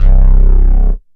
SYNTH BASS-1 0009.wav